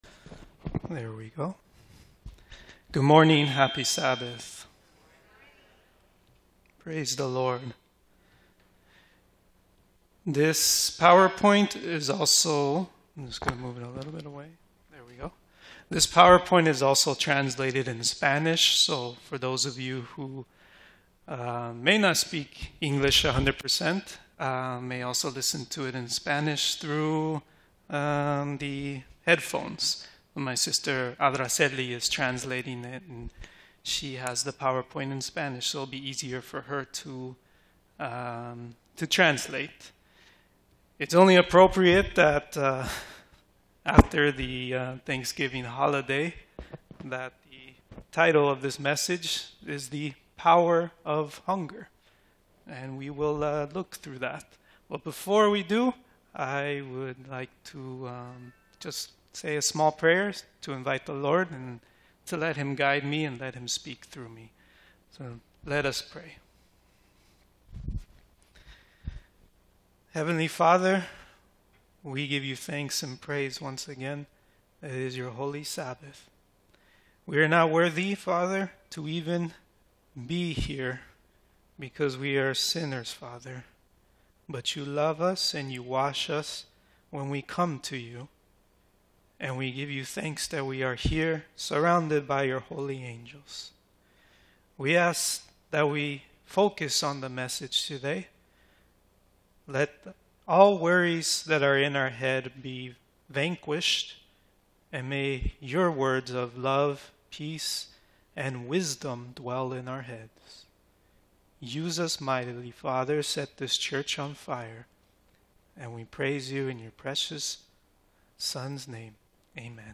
Seventh-day Adventist Church
Sermons